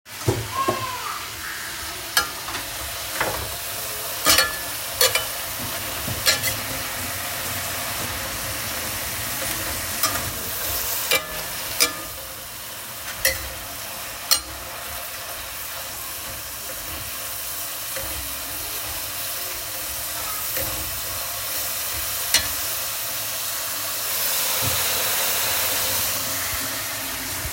這兩個聲音，給大家猜猜哪個是下雨聲，哪個是炒菜聲？
news033-炒菜聲？下雨聲？3.m4a